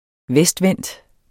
Udtale [ -ˌvεnˀd ]